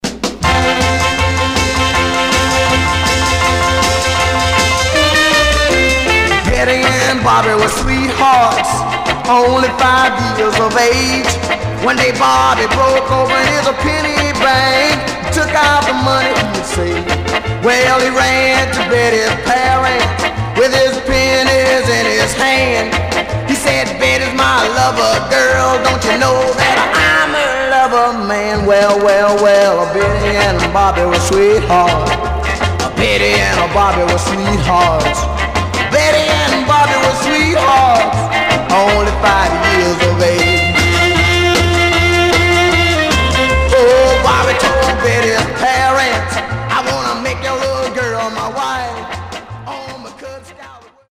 Mono
Teen